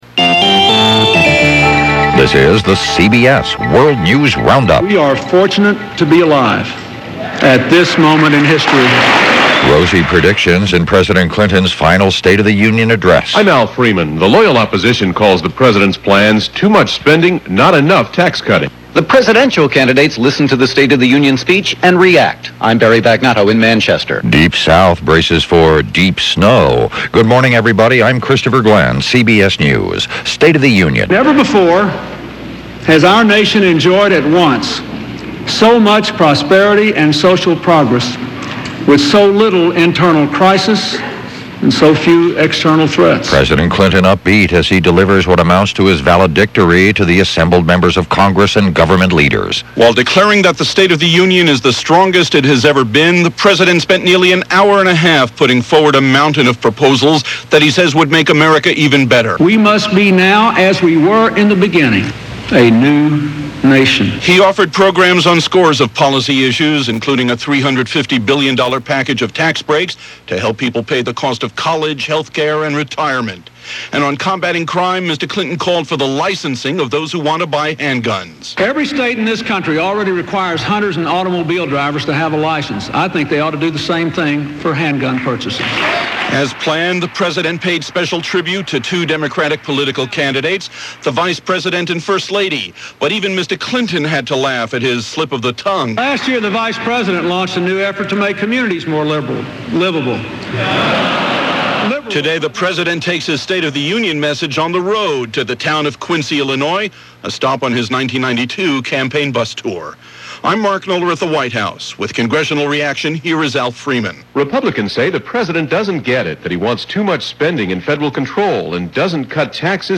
A Clinton State Of The Union - The East Buried In Snow - Fun At Work Day - January 28, 2000 - CBS World News Roundup